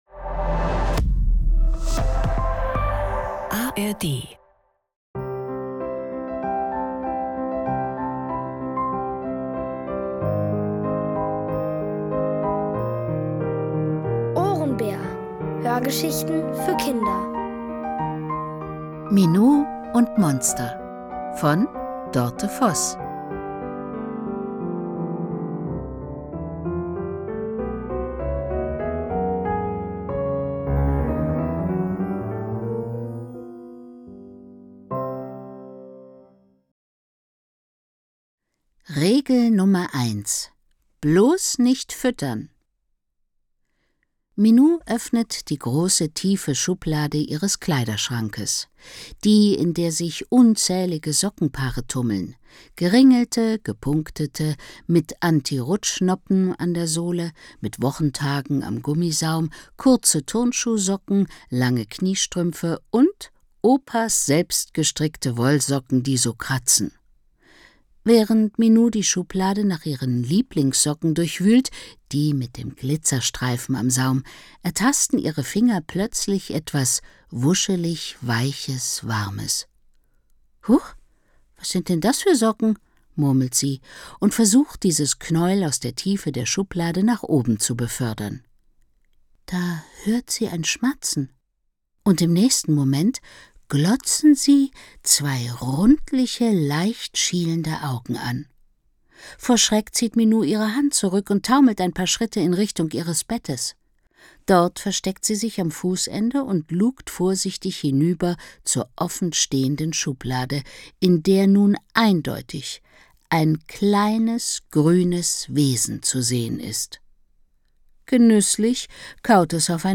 Minou und Monster | Die komplette Hörgeschichte! ~ Ohrenbär Podcast